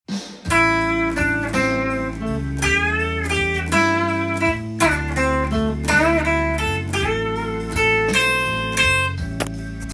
ブルースで使用される基本リズムは①のようなハネのリズムや
以下のようなギターソロになります。
基本リズム①とフレーズ①